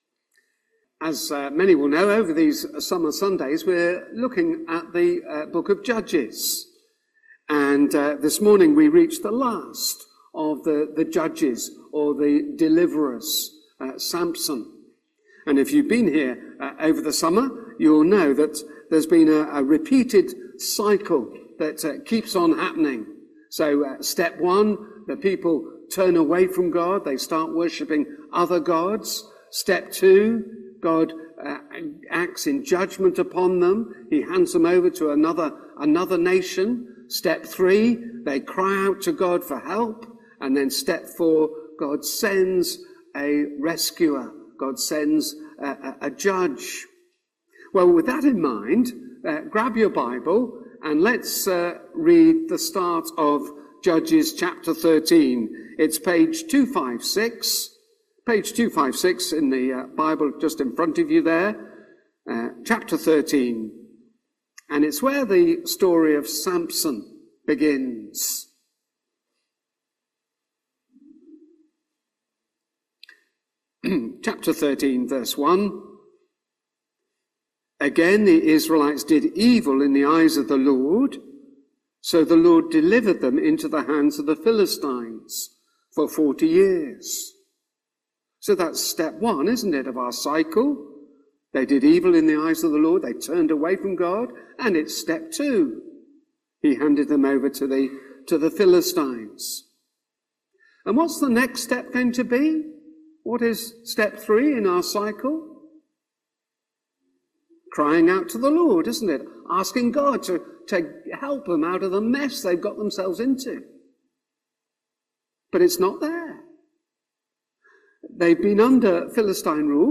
Reading starts at 18.28 after All Age talk